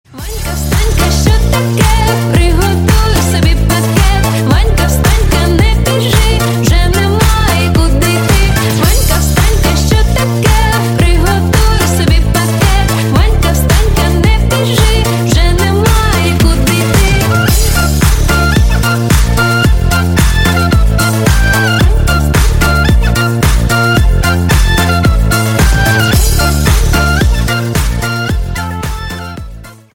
Весёлые Рингтоны
Поп Рингтоны